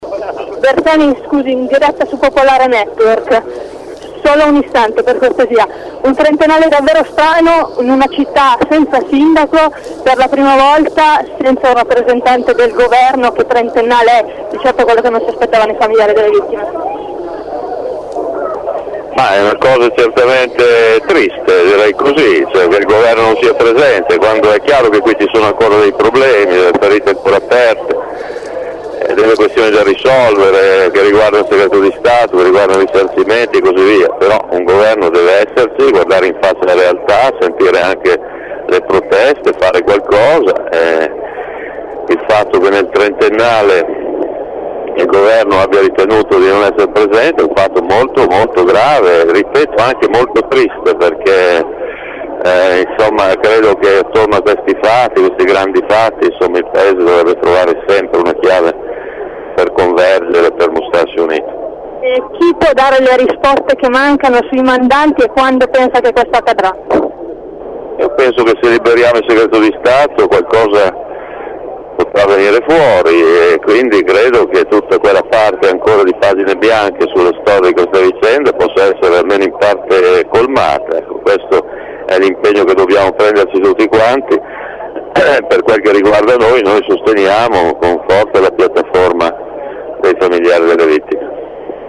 La pensa così il segretario nazionale del Partito Democratico, Pier Luigi Bersani, raggiunto dai nostri microfoni in via Indipendenza durante il corteo del 2 agosto.